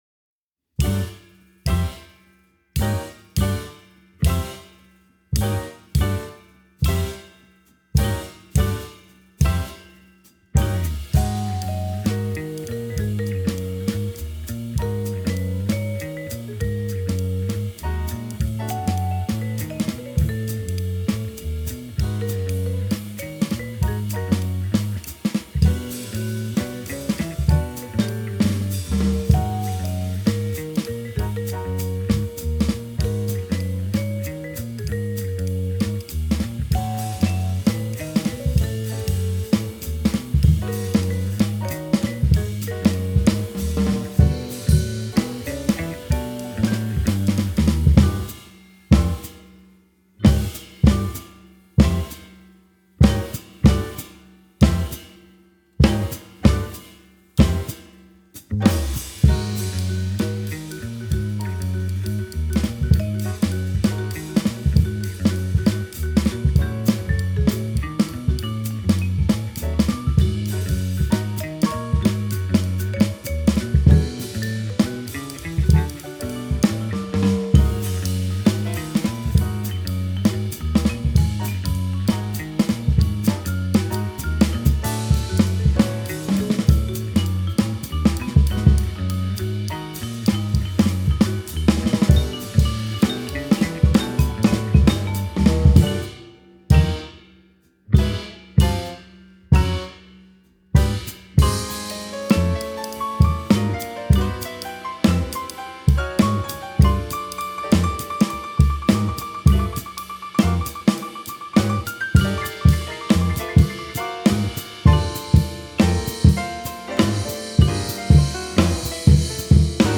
Le quatuor new-yorkais
session d'enregistrement à l'université d'état de Fredonia
batterie
basse et guitare électrique
vibraphone et piano électrique